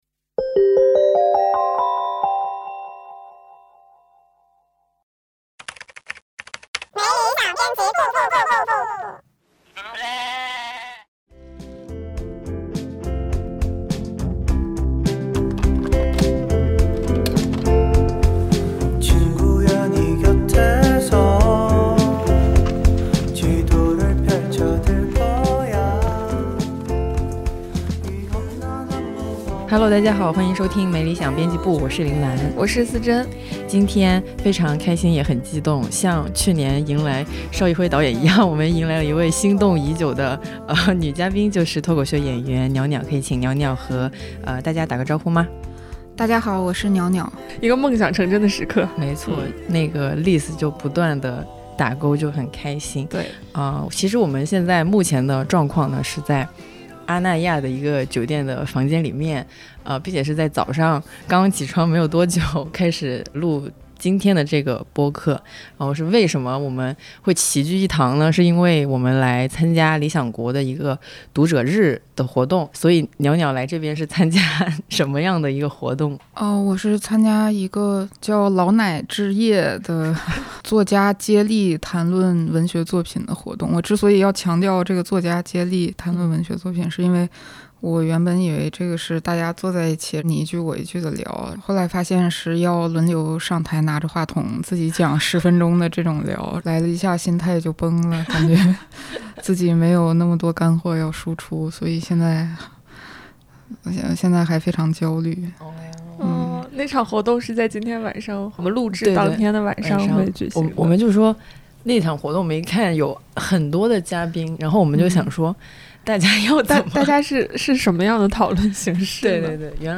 本期是无主题闲聊，如果你对我们不是很了解，可以跳过啦！